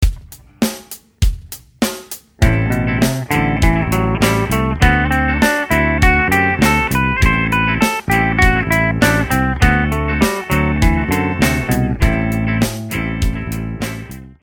Dorian is a minor mode which means that it works in a minor key. The exercises here will use the A Dorian mode which uses the notes of the G major scale starting on A (A B C D E F# G) and played in the key of A minor.
dorian_a.mp3